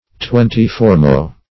Twenty-fourmo \Twen`ty-four"mo\